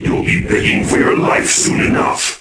Lusikiel-Vox_Skill3_1.wav